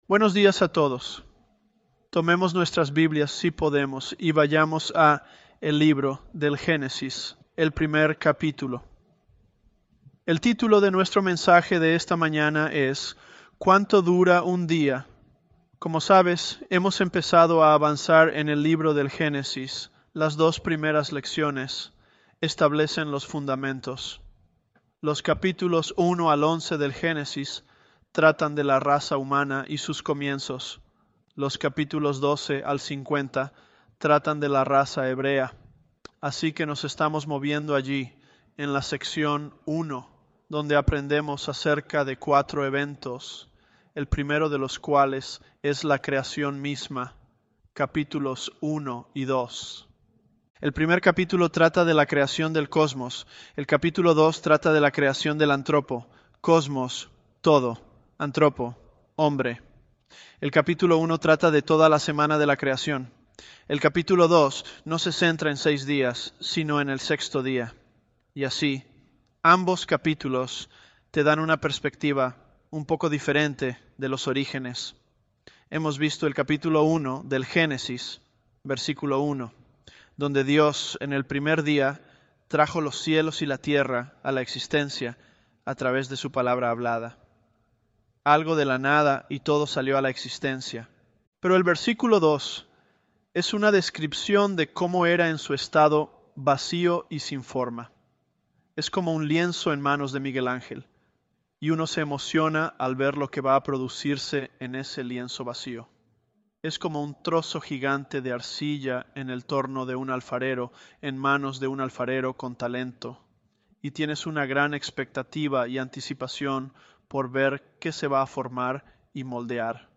ElevenLabs_Genesis-Spanish004.mp3